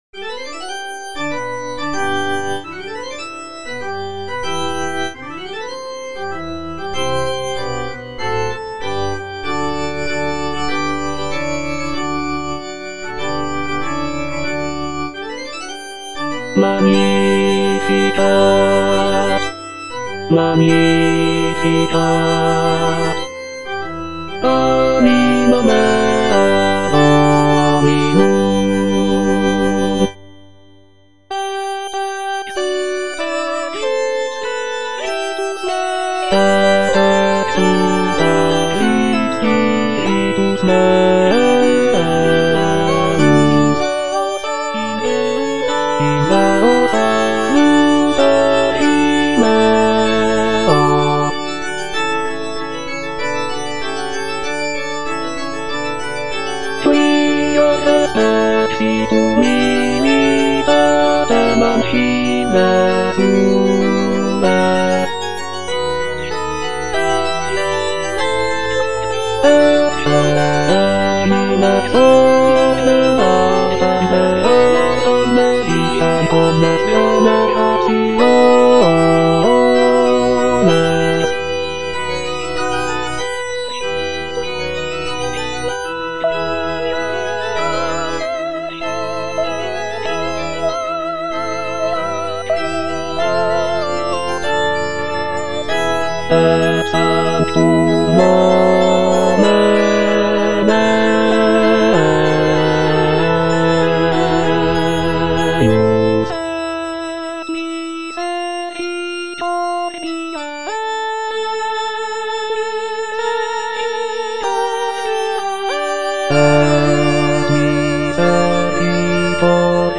B. GALUPPI - MAGNIFICAT Magnificat anima mea - Bass (Emphasised voice and other voices) Ads stop: auto-stop
"Magnificat" by Baldassare Galuppi is a sacred choral work based on the biblical text of the Virgin Mary's song of praise from the Gospel of Luke.